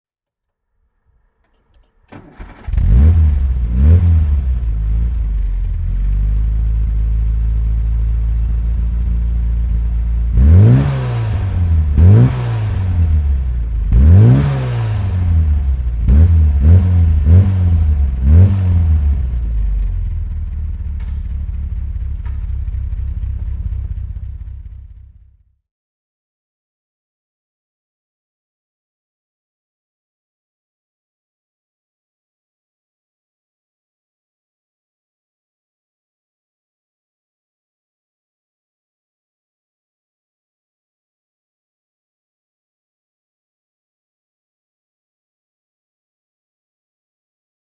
Engine sounds of Morris vehicles (random selection)